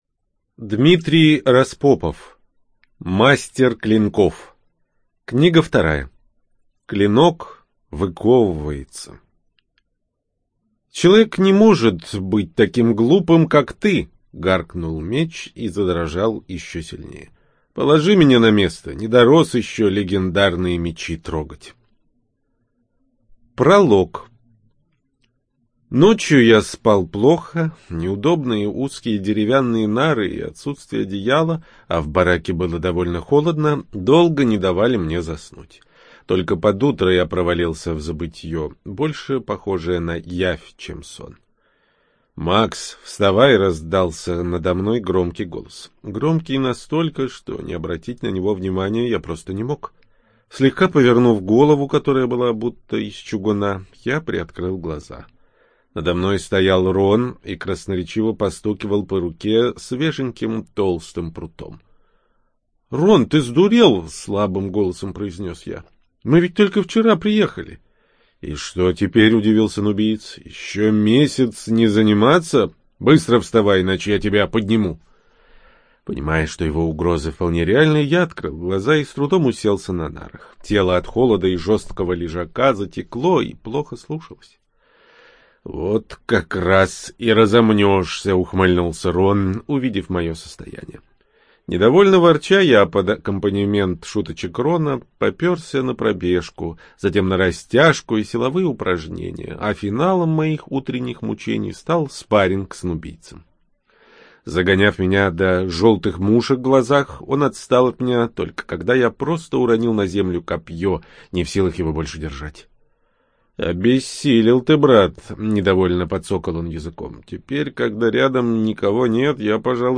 БиблиотекаКниги → Мастер клинков-02. Клинок выковывается